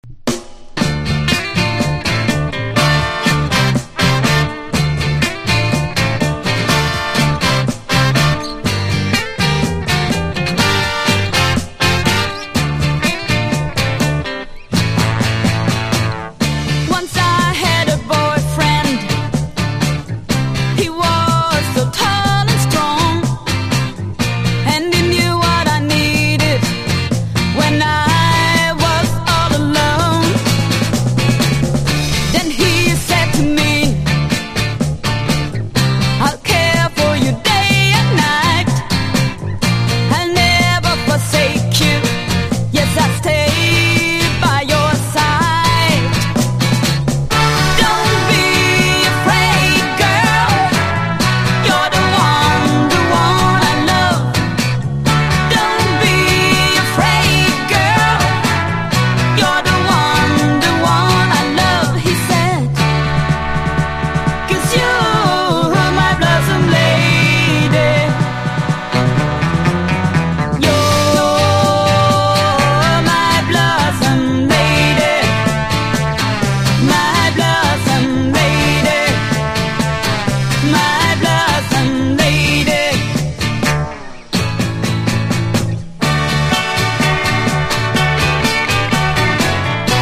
ポップスだけでなく、サイケ･ロックなど幅広いサウンドを聴かせます。